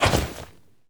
foley_jump_movement_throw_07.wav